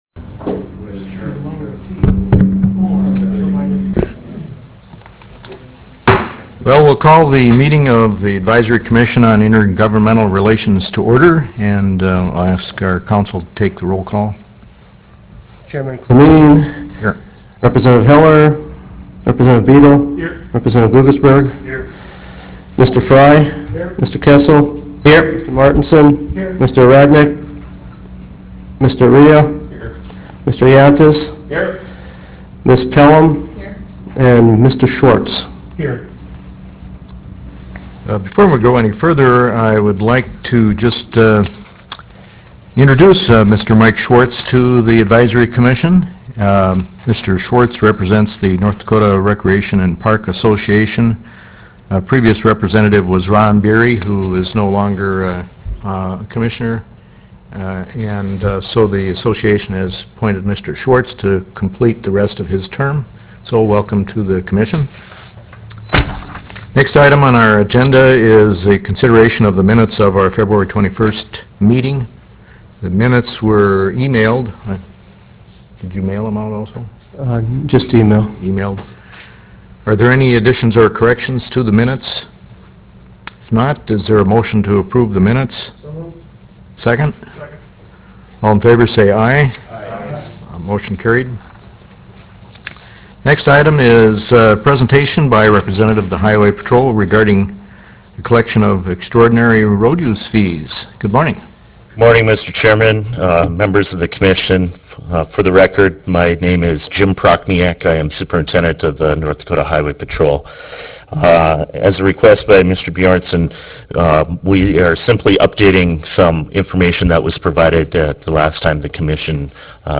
Roughrider Room State Capitol Bismarck, ND United States